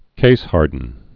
(kāshärdn)